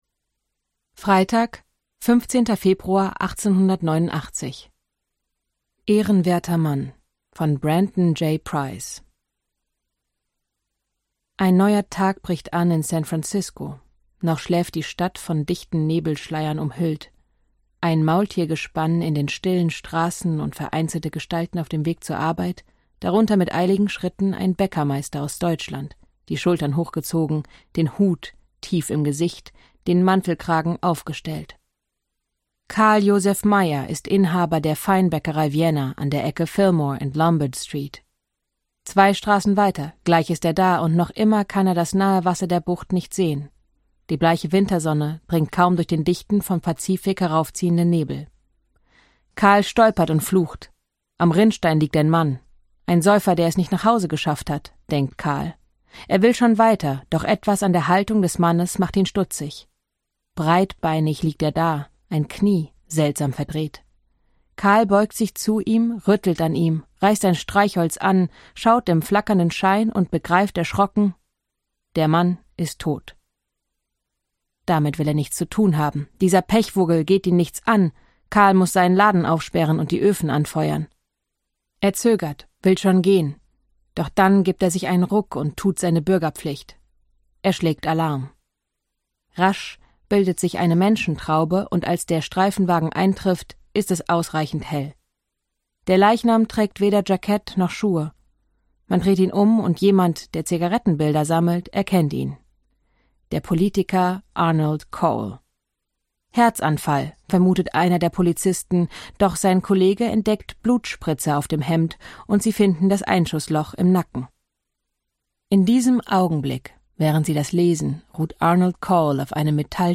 Mala Emde , Fabian Busch (Sprecher)